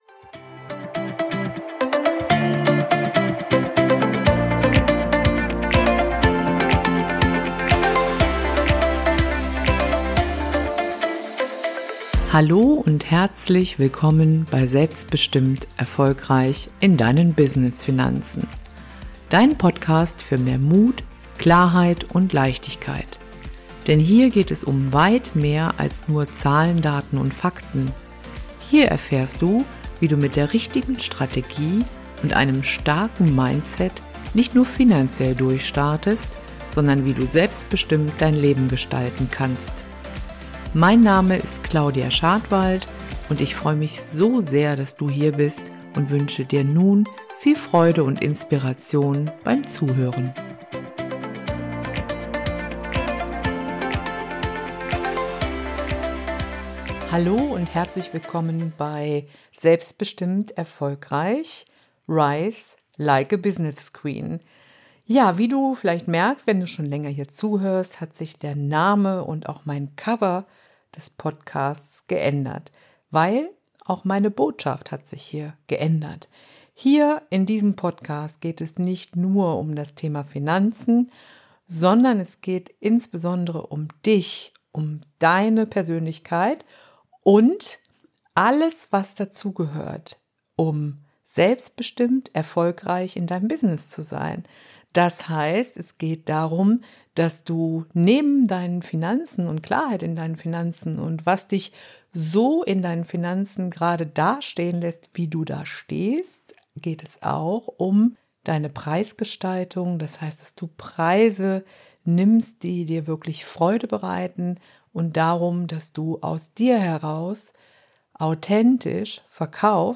In dieser Solo-Folge spreche ich ehrlich und tiefgründig über das, was viele Unternehmerinnen blockiert – die unsichtbare Rüstung, die wir uns im Laufe unseres Lebens aufgebaut haben.